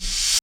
RevOpenHH FinalVinyl.wav